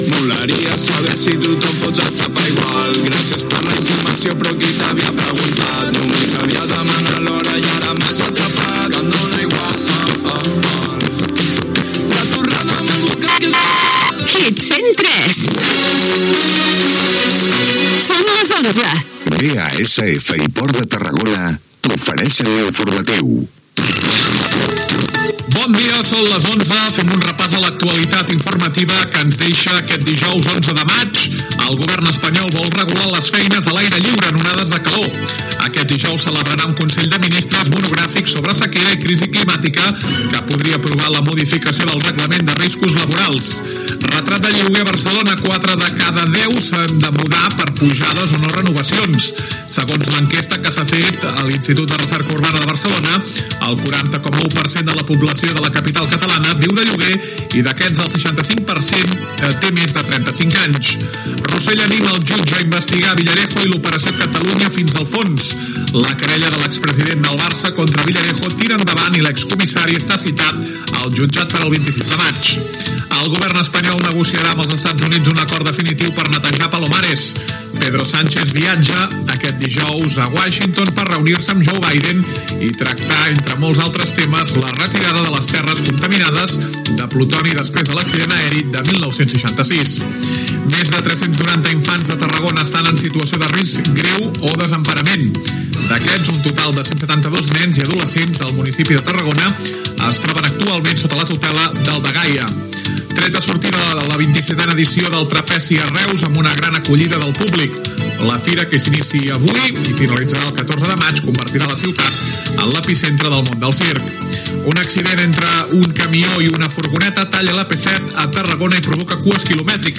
Tema musical, indicatiu de l'emissora, hora, publicitat, informatiu, publicitat, tema musical.
Informatiu
FM
Qualitat de l'àudio defectuosa